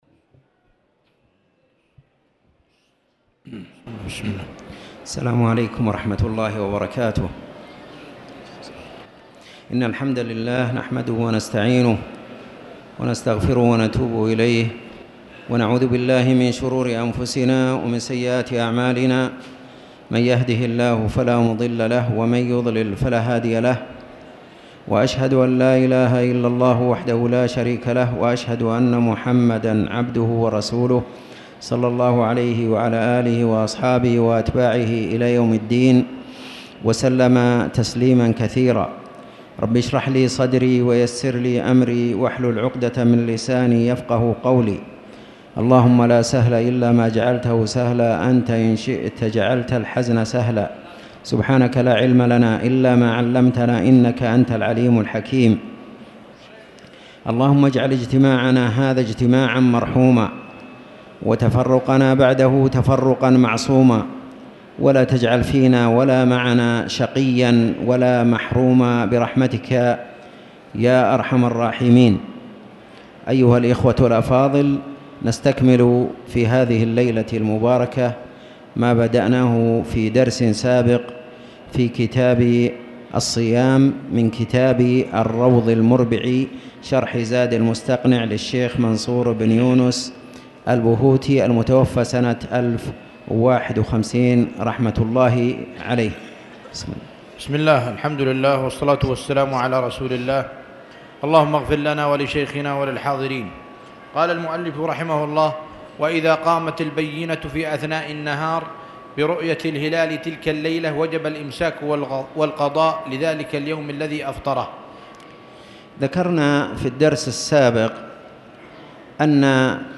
تاريخ النشر ١٣ ربيع الأول ١٤٣٨ هـ المكان: المسجد الحرام الشيخ